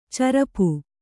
♪ carapu